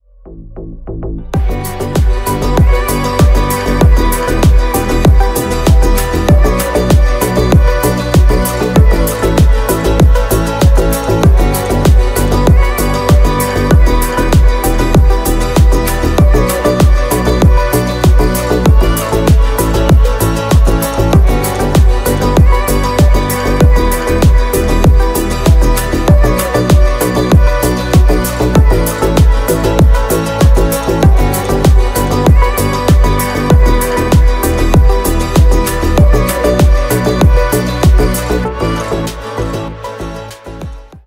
• Качество: 320, Stereo
deep house
Electronic
спокойные
красивая мелодия
танцевальные